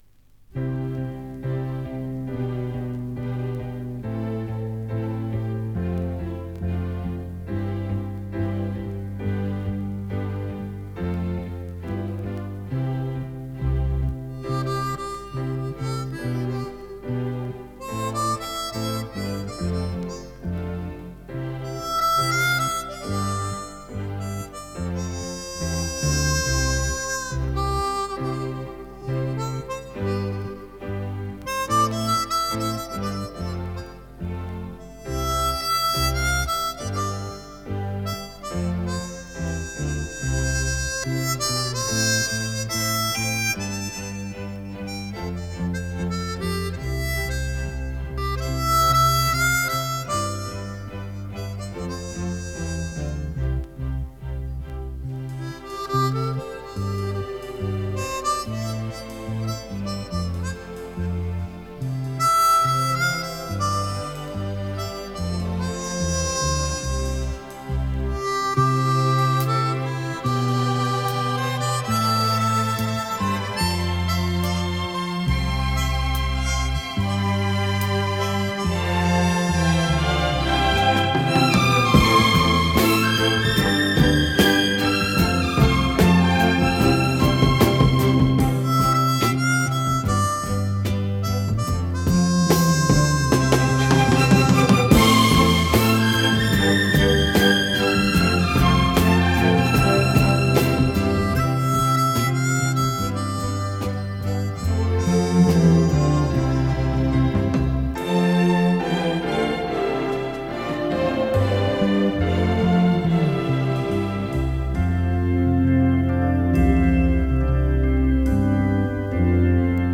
Жанр: Easy Listening
Носитель: LP
Код класса состояния винила: NM-
Обработка: без обработки